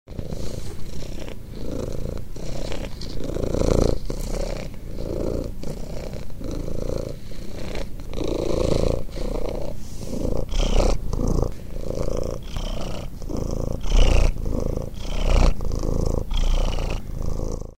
• Качество: 320, Stereo
кошка
мурлыканье кошки